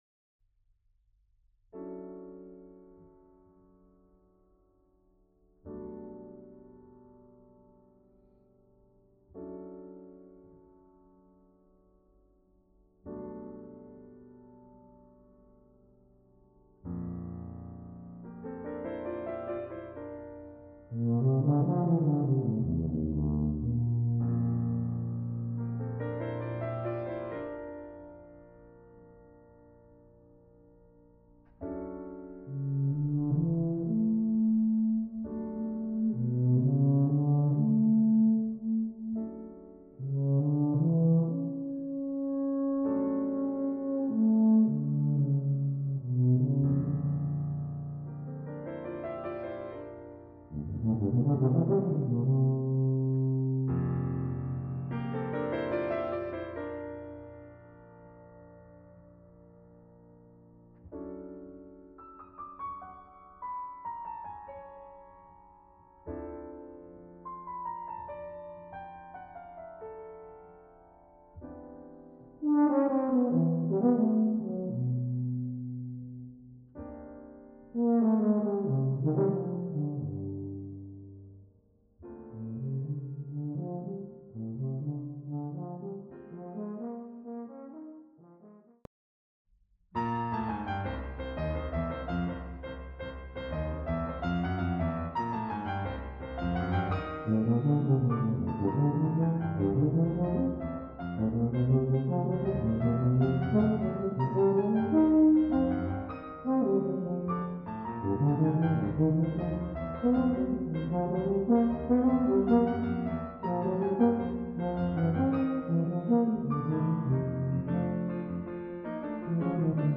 is a 3 movement work
Melodically the lines create a sense of improvisation.
and harmonically it is considered free tonality.